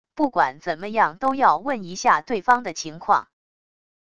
不管怎么样都要问一下对方的情况wav音频生成系统WAV Audio Player